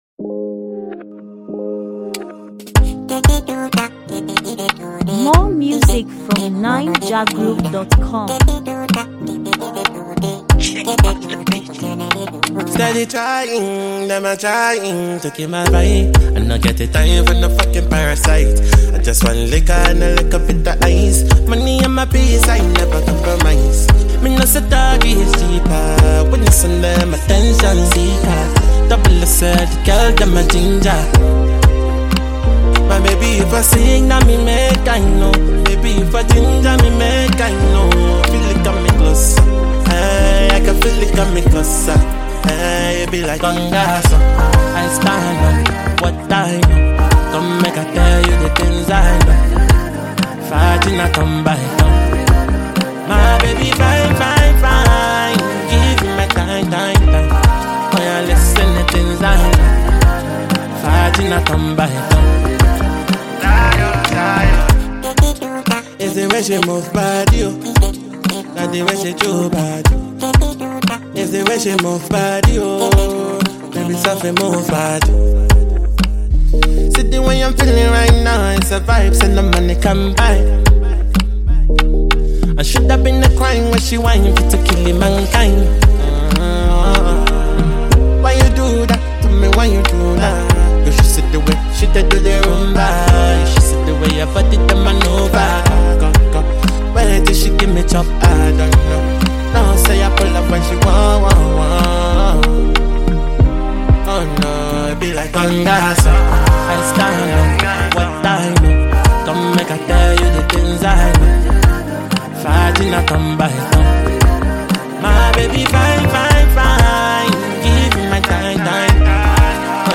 Naija-music
Prominent Nigerian Afrobeat singer and songsmith